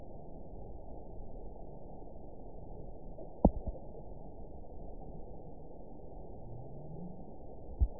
event 921114 date 04/28/24 time 16:52:38 GMT (1 year, 1 month ago) score 9.03 location TSS-AB04 detected by nrw target species NRW annotations +NRW Spectrogram: Frequency (kHz) vs. Time (s) audio not available .wav